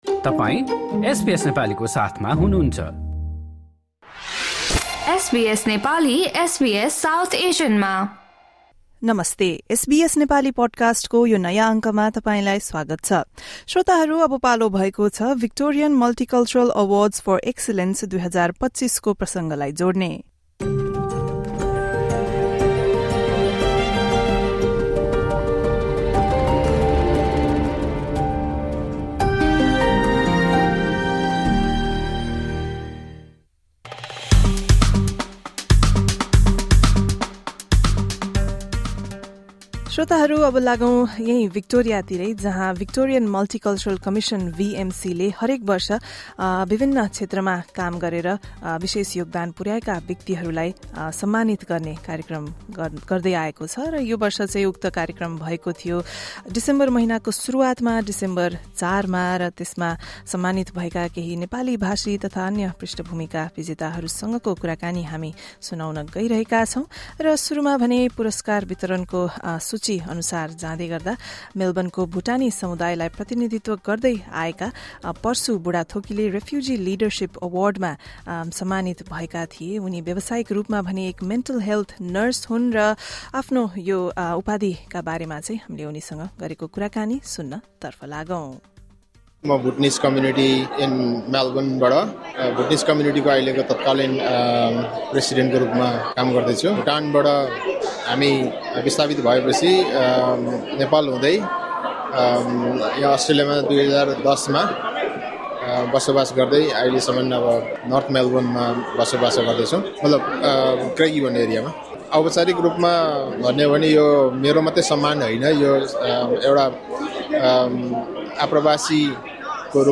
We also spoke with Victoria Police Chief Commissioner Mike Bush and other award recipients present at the event.